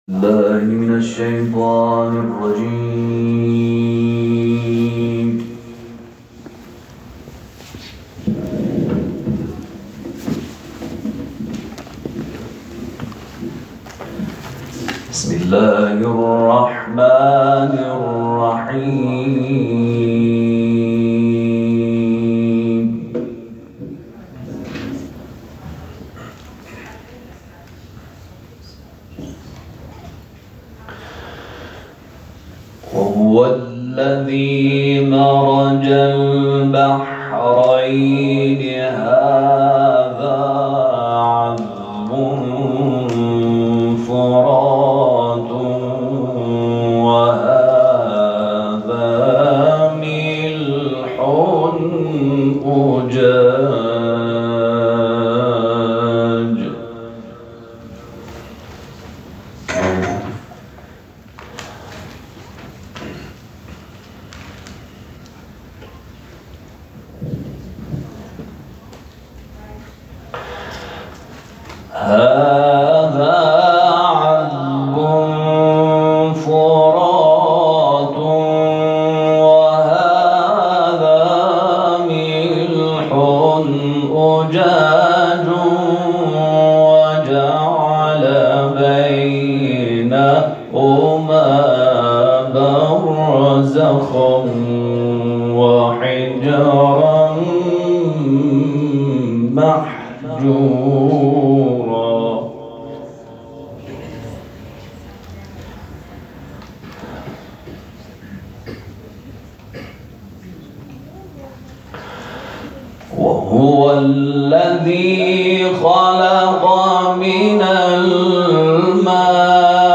در جریان افتتاحیه مرحله استانی سی‌اُمین دوره مسابقات قرآن و عترت بسیج در شهرکرد اجرا شد
قاری ممتاز
صوت تلاوت